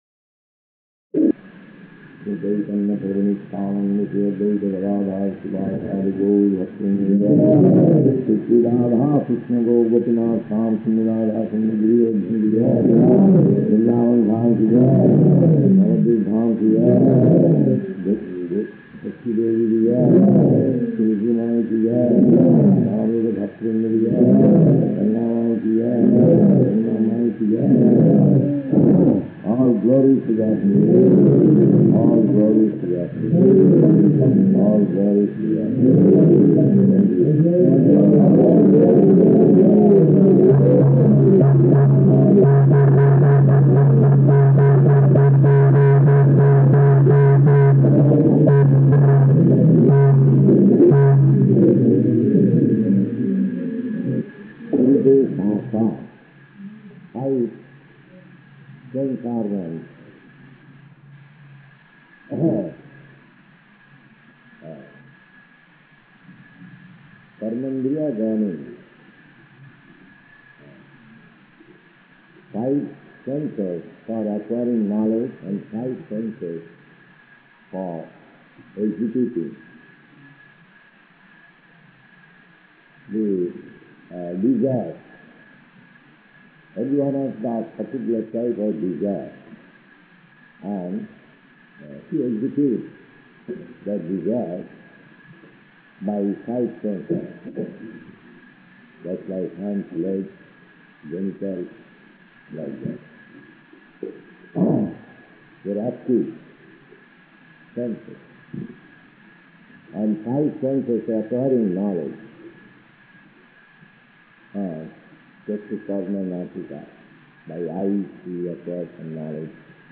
Lecture
Lecture --:-- --:-- Type: Lectures and Addresses Dated: December 27th 1970 Location: Surat Audio file: 701227LE-SURAT.mp3 Prabhupāda: [ prema-dhvani ] All glories to the assembled devotees.